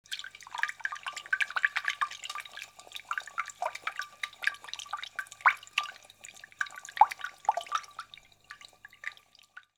household
Glass Bottle Fill